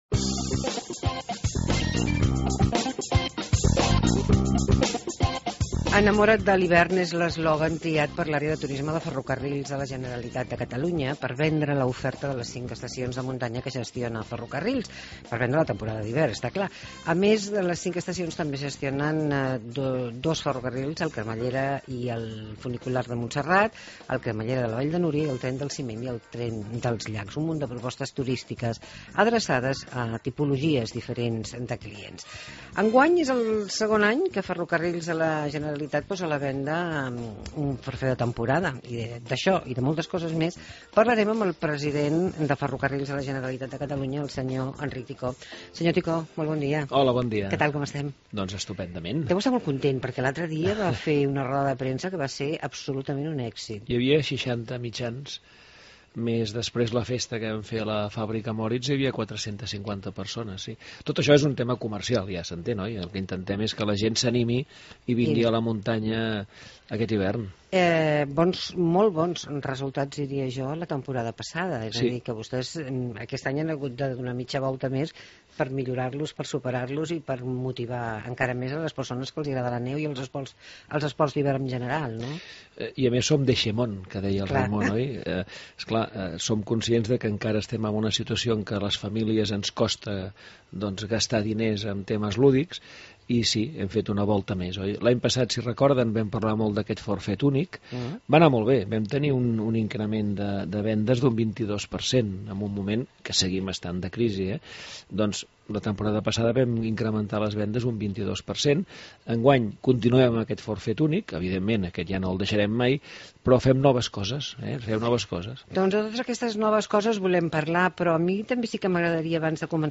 Entrevista amb Enric Ticó, president de FGC